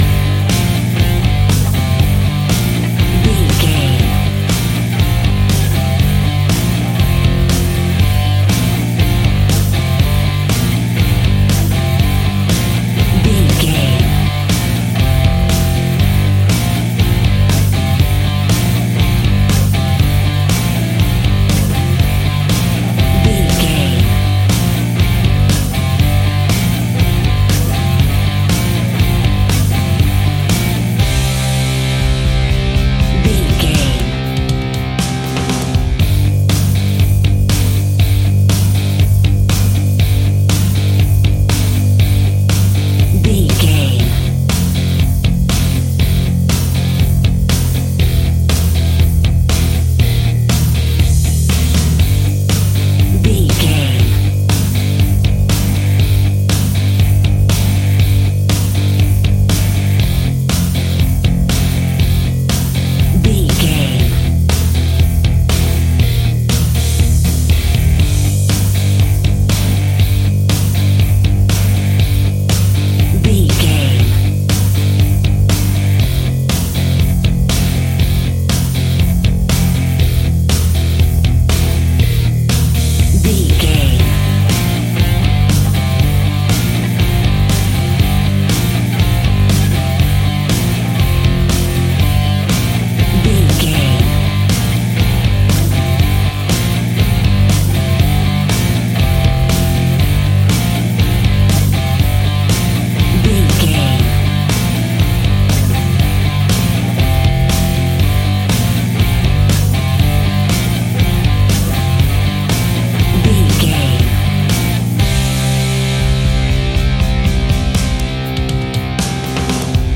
Epic / Action
Fast paced
Aeolian/Minor
hard rock
heavy metal
blues rock
distortion
instrumentals
Rock Bass
heavy drums
distorted guitars
hammond organ